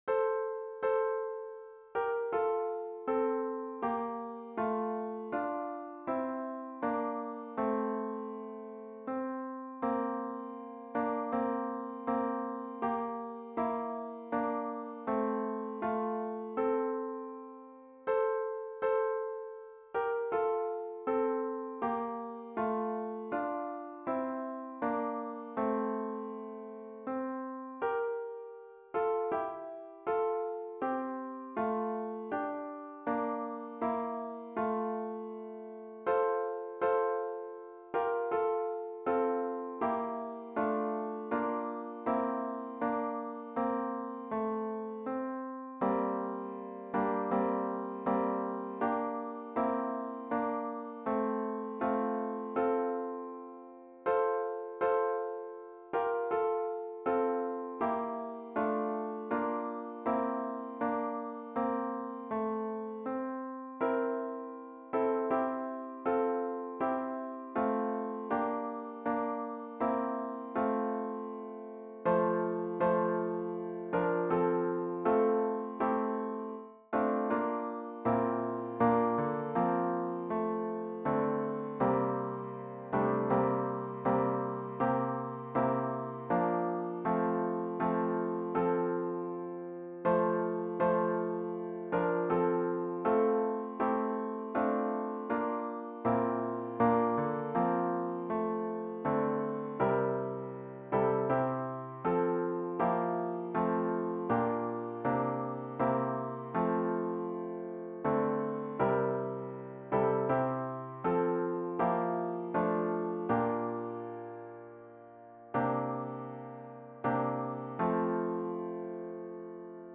A capella arrangements for mixed chorus.